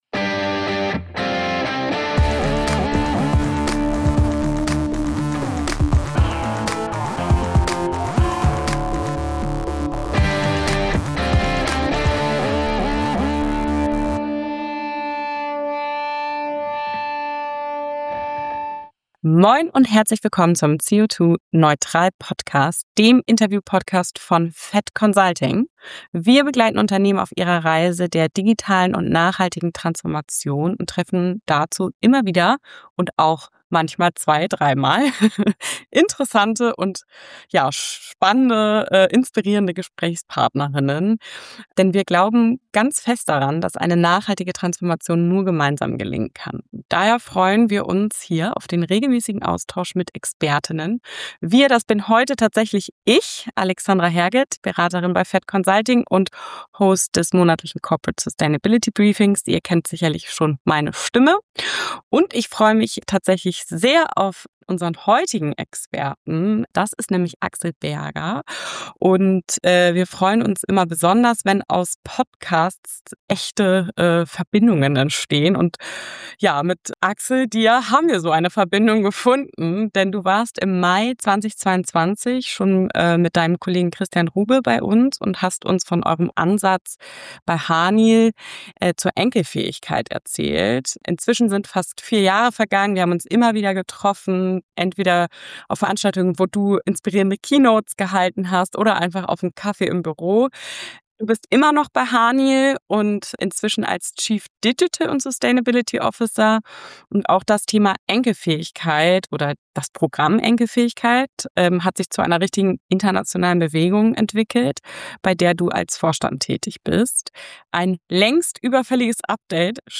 Nachhaltigkeit wirtschaftlich denken und wirklich verankern - so gelingt es bei Haniel ~ CEO2-neutral - Der Interview-Podcast für mehr Nachhaltigkeit im Unternehmen Podcast